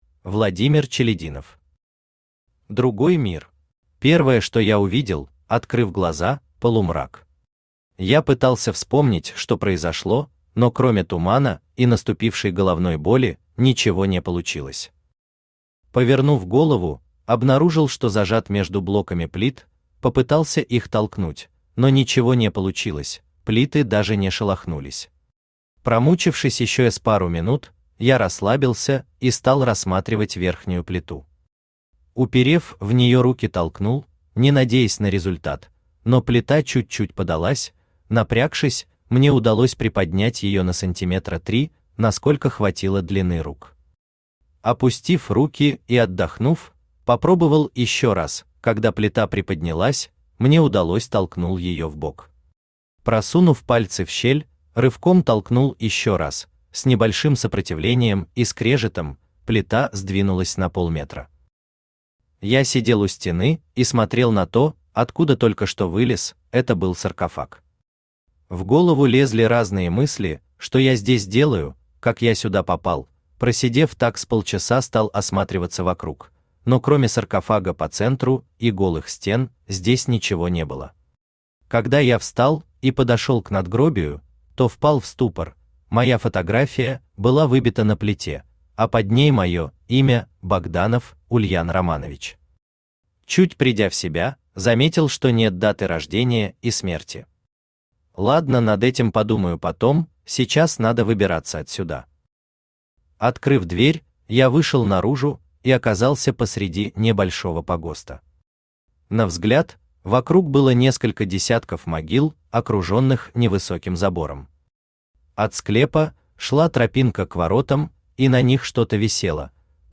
Аудиокнига Другой мир | Библиотека аудиокниг
Aудиокнига Другой мир Автор Владимир Иванович Челядинов Читает аудиокнигу Авточтец ЛитРес.